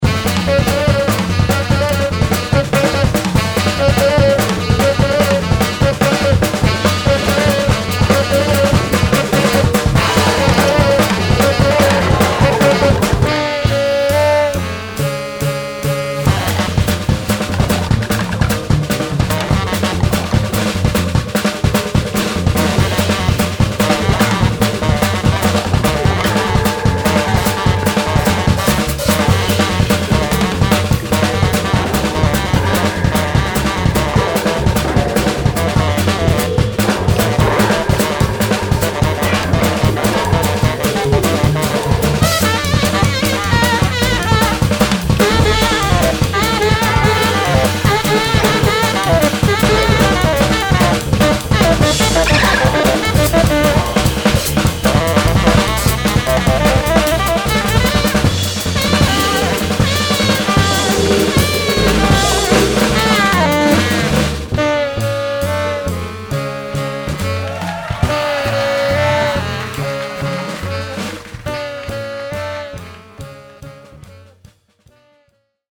Oh.....here is a bit of a live gig i found today